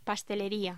Locución: Pastelería
voz